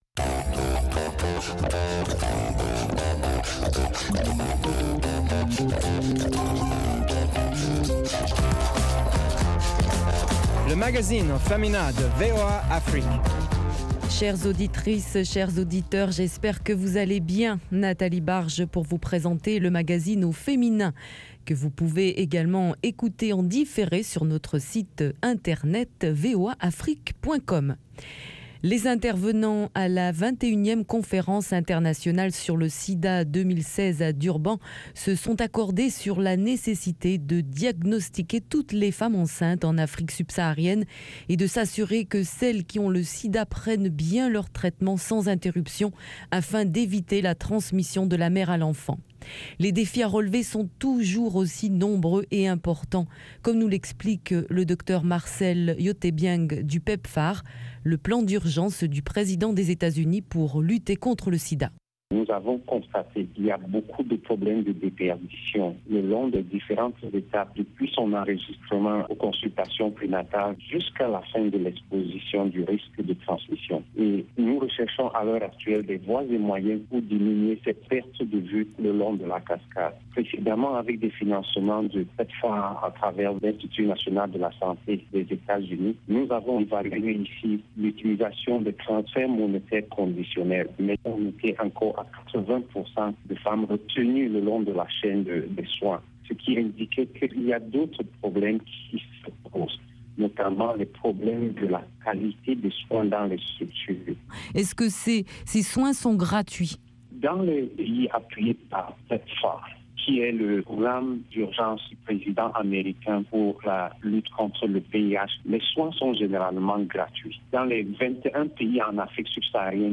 LMF présente également des reportages exclusifs de nos correspondants sur le continent.